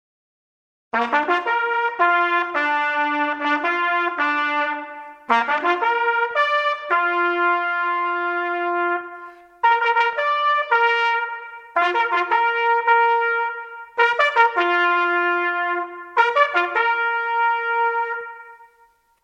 Hejnał